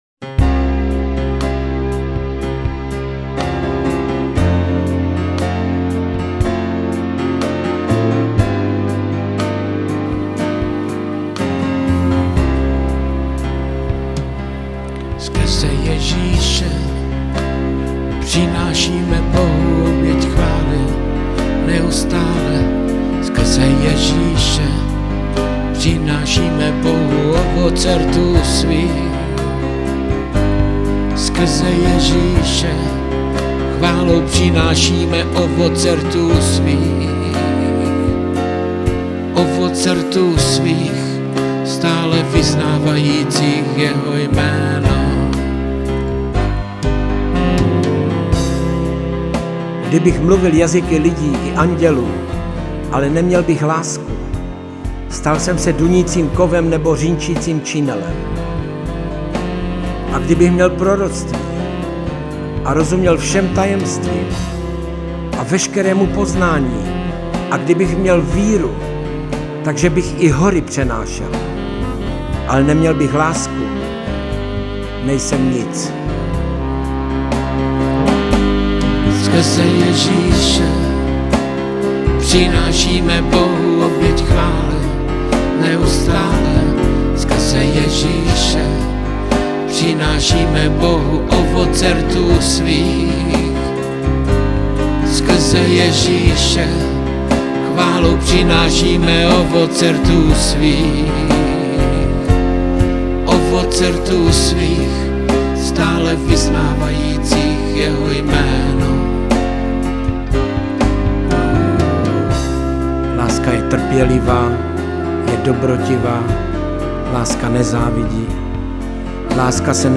Křesťanské písně
Písně ke chvále a uctívání